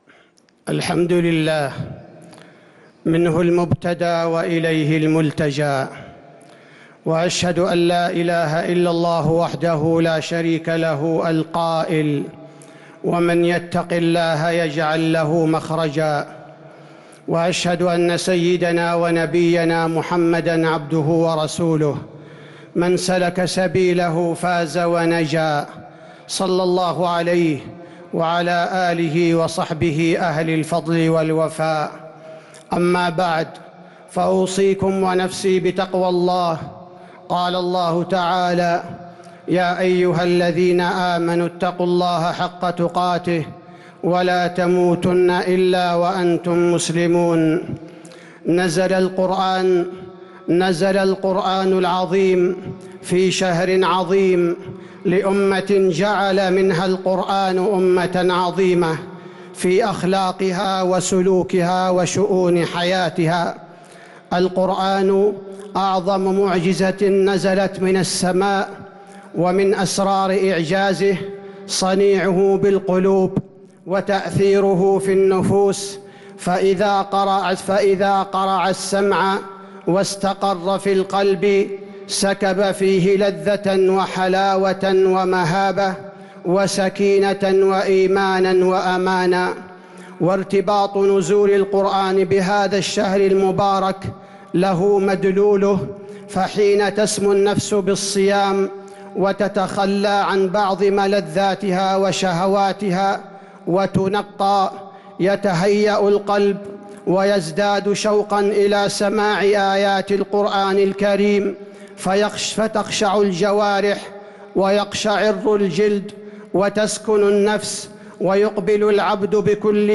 خطبة الجمعة 7-9-1443هـ | khutbat aljumuea 8-4-2022 > خطب الحرم النبوي عام 1443 🕌 > خطب الحرم النبوي 🕌 > المزيد - تلاوات الحرمين